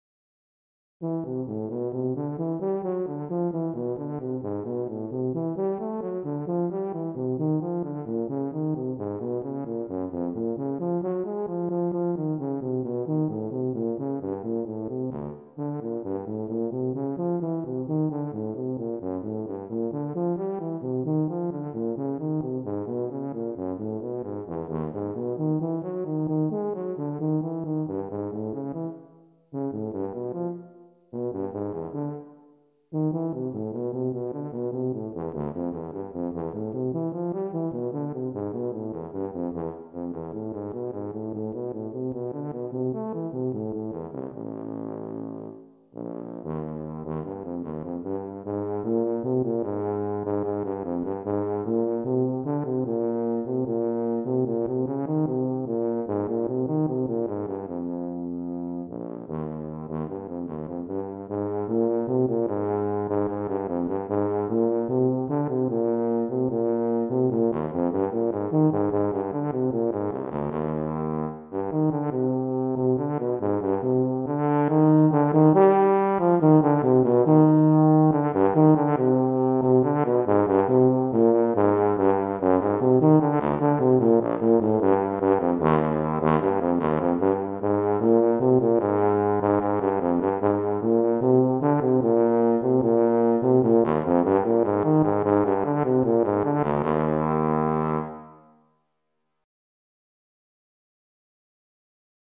Voicing: Tuba Methods/Studies/Etudes